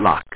w2_lock.mp3